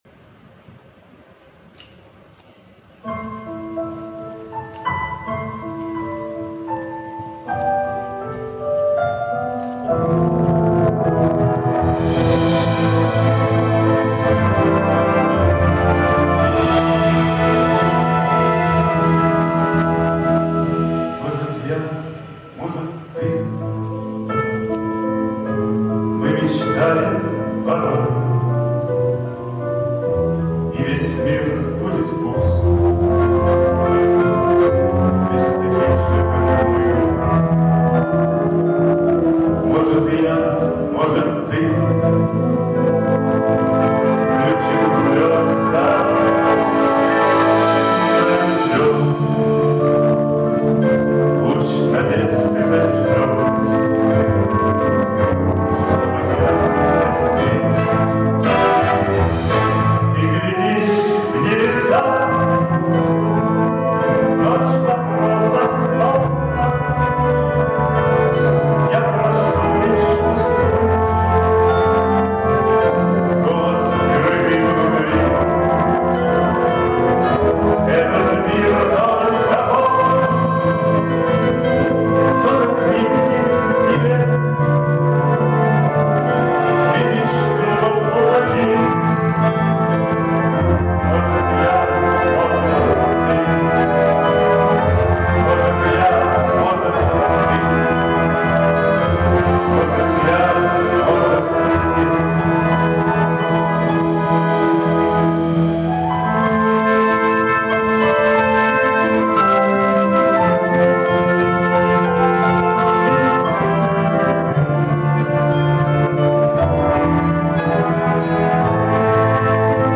Новогодний концерт в театре им.М.Н. Ермоловой.
Качество ужасное.